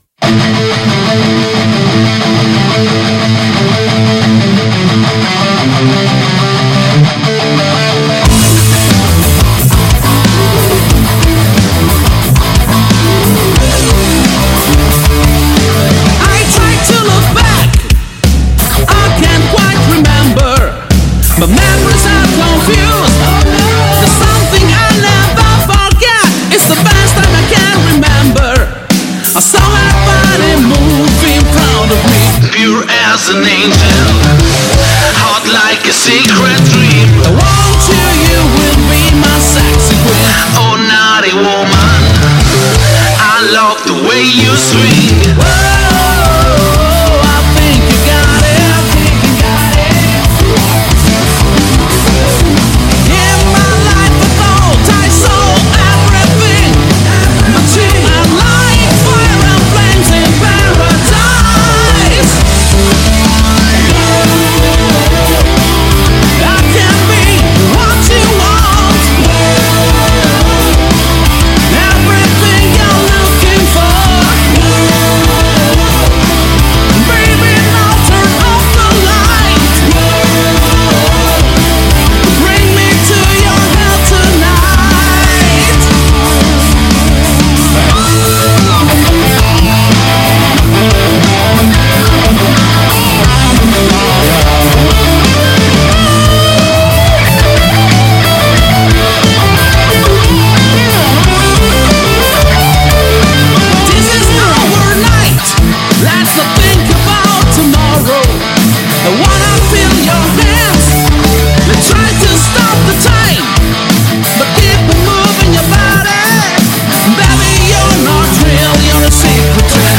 Intervista MADHOUSE | 14-11-22 | Radio Città Aperta